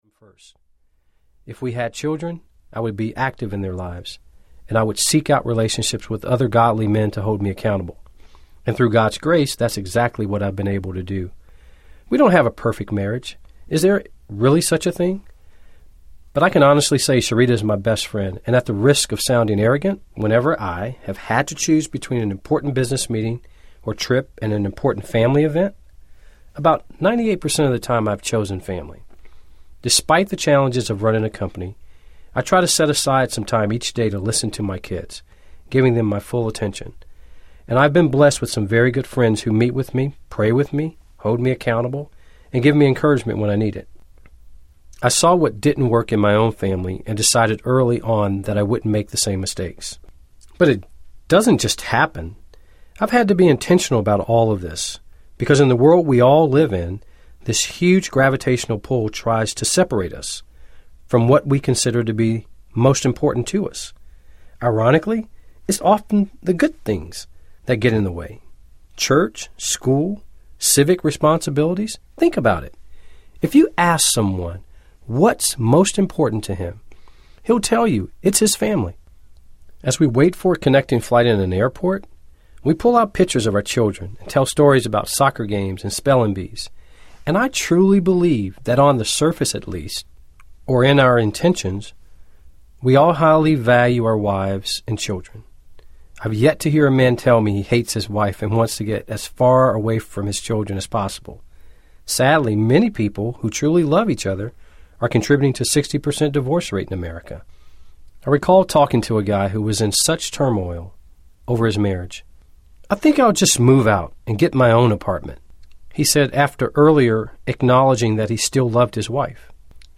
Treat Me Like a Customer Audiobook